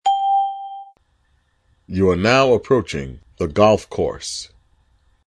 Here's the poi-factory's alert sounds;
golf.mp3